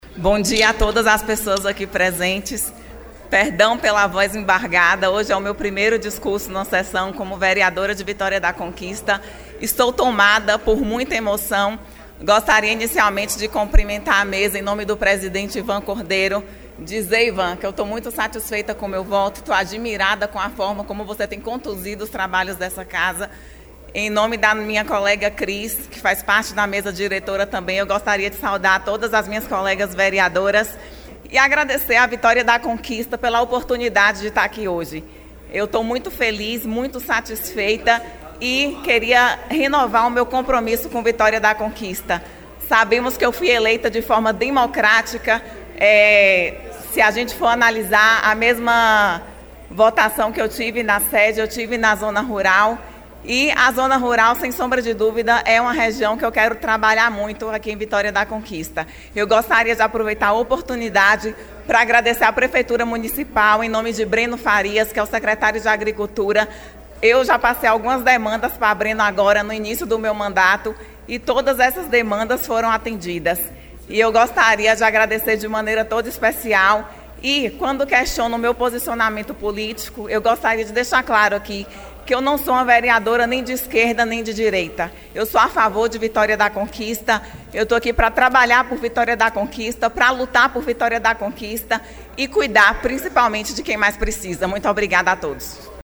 Em seu primeiro discurso como vereadora, na sessão ordinária desta sexta-feira (7) Leia de Quinho (PSD) reafirmou o seu compromisso com a cidade de Vitória da Conquista.